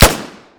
sounds / weapons / thompson / m1a1_3.ogg